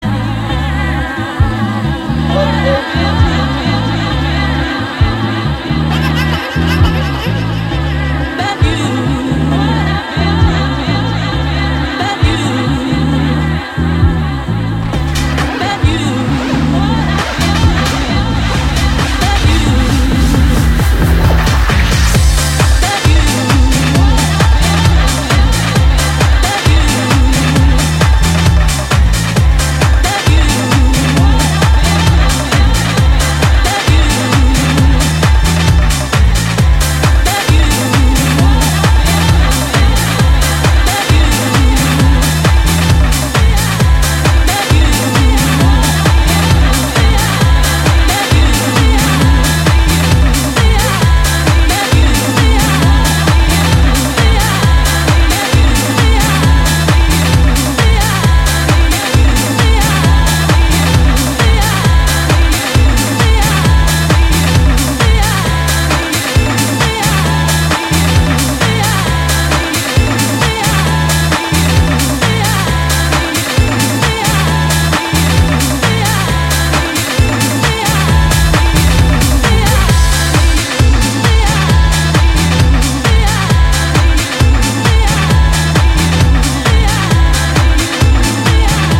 執拗なディスコサンプルの反復とエフェクトの応酬で畳み掛ける、BPM130前後のキラーチューン全4曲を収録。